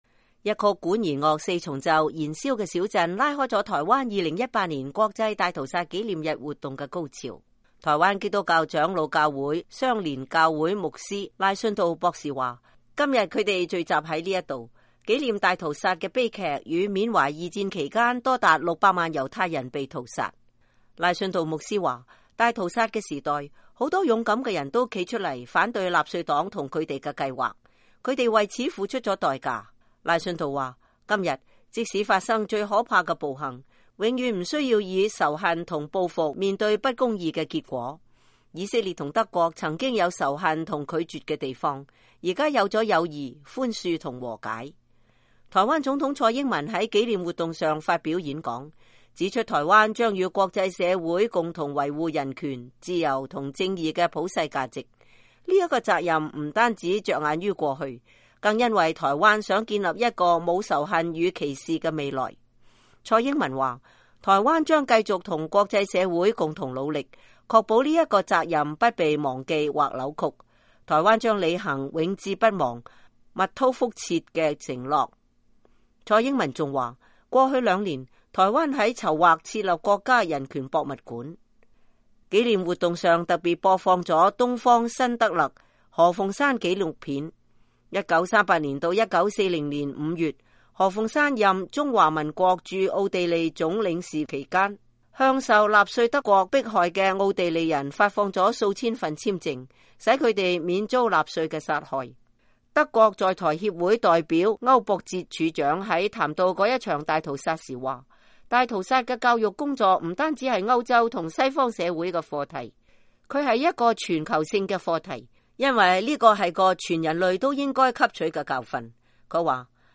台灣總統蔡英文講話
一曲管弦樂四重奏“燃燒的小鎮”拉開了台灣“2018 國際大屠殺紀念日”活動的高潮。